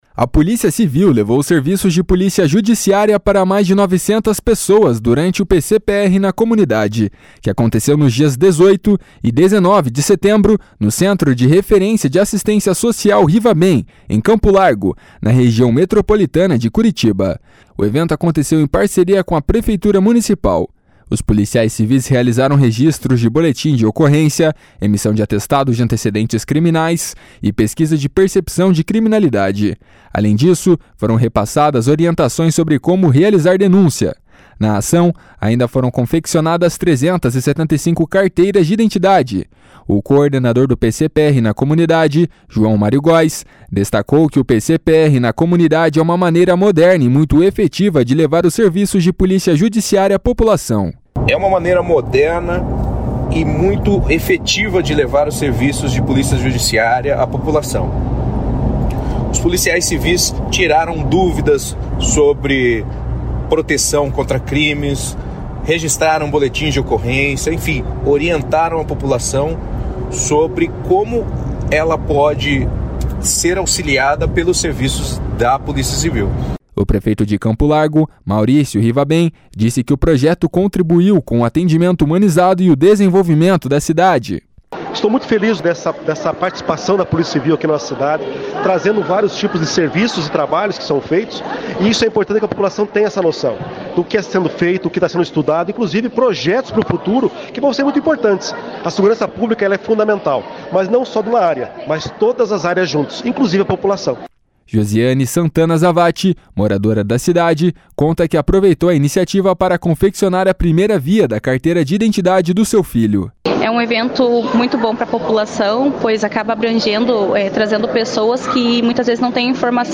O prefeito de Campo Largo, Maurício Rivabem, disse que o projeto contribuiu com o atendimento humanizado e o desenvolvimento da cidade. // SONORA MAURÍCIO RIVABEM //